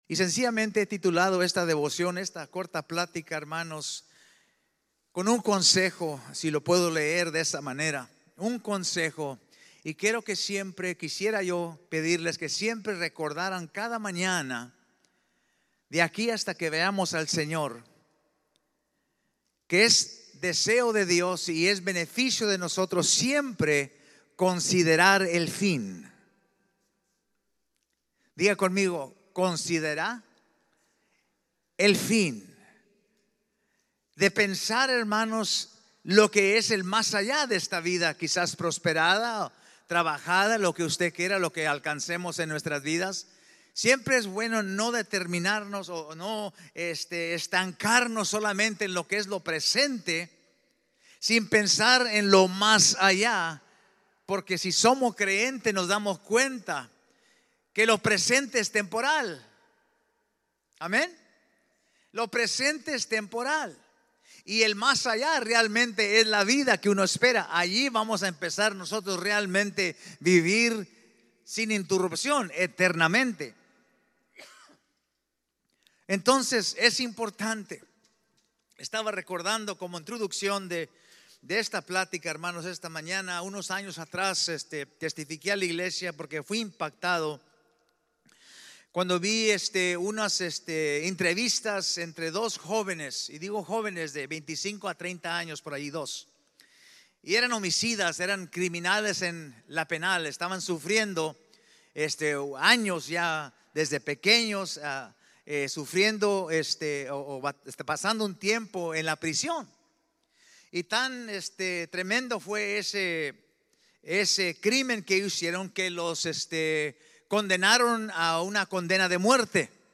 2-16-25-ESP-Sermon-Podcast.mp3